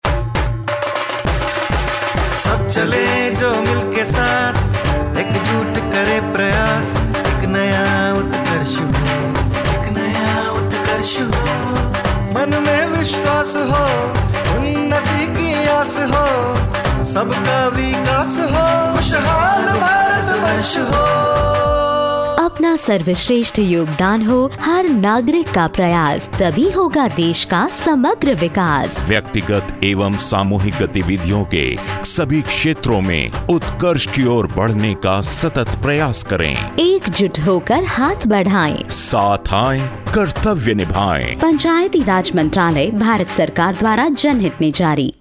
Radio Jingle